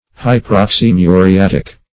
Search Result for " hyperoxymuriatic" : The Collaborative International Dictionary of English v.0.48: Hyperoxymuriatic \Hy`per*ox`y*mu`ri*at"ic\, a. (Chem.) Perchloric; as, hyperoxymuriatic acid.